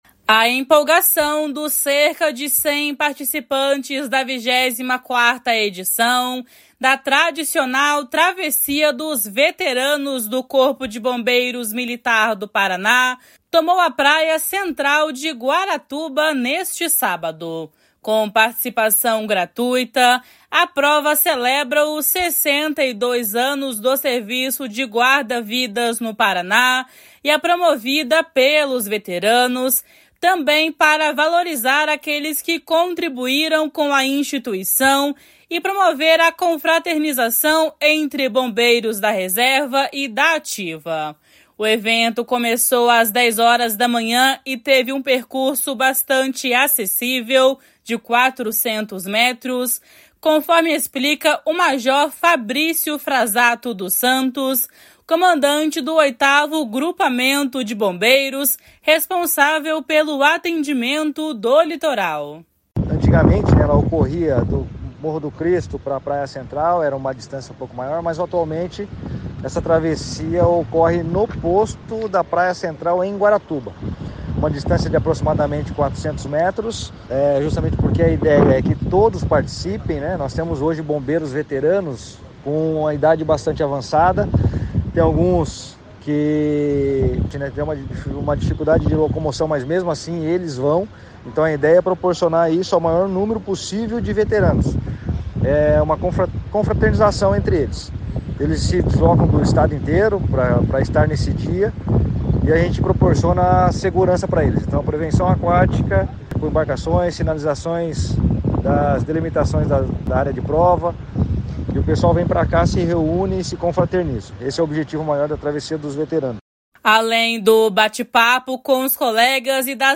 Além do bate-papo com os colegas e das braçadas durante a prova, o encontro também tem um ingrediente que nunca falta onde se encontram tantos bombeiros orgulhosos da farda: a canção do Soldado de Fogo, cantada em coro pelos presentes. Tudo acompanhado de perto com muita curiosidade pelo público que passava pelo calçadão da Praia Central de Guaratuba.